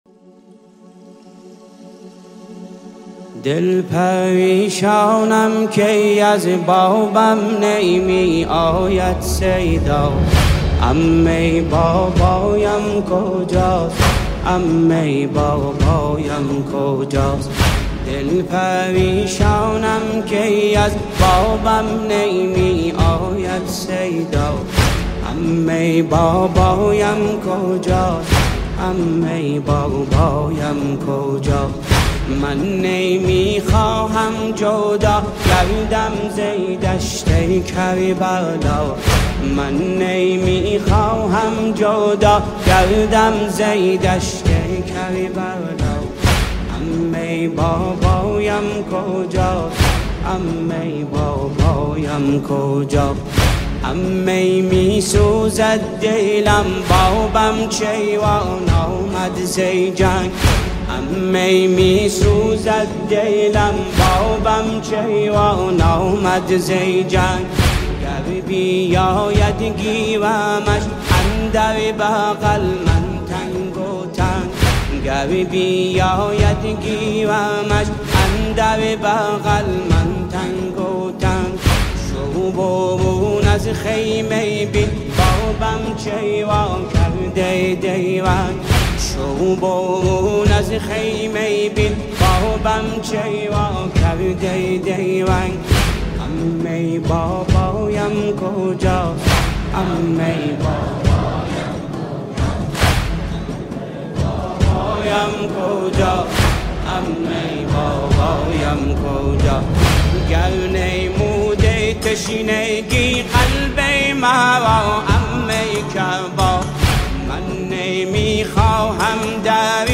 دانلود مداحی دلنشین «ذوالجناح» || عمه بابام کجاست || ویژه ماه محرم